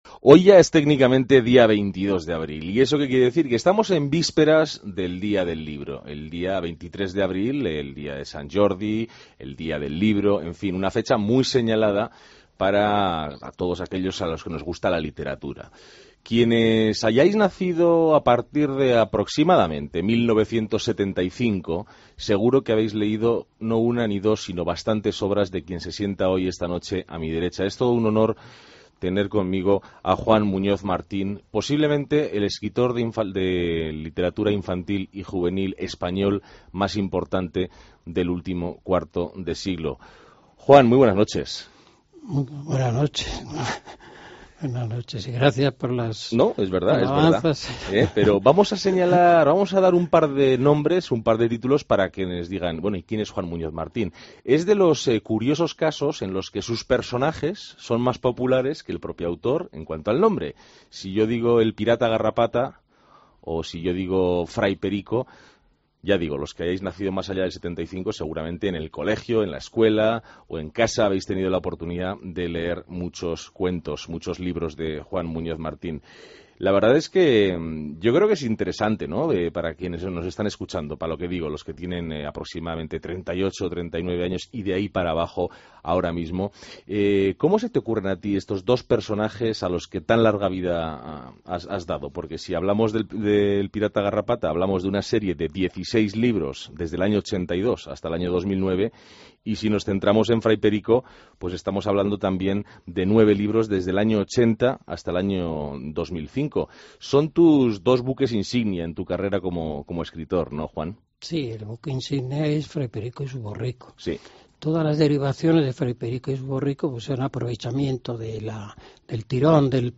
AUDIO: Charlamos con el autor de literatura infantil de series protagonizadas por Fray Perico o por El Pirata Garrapata.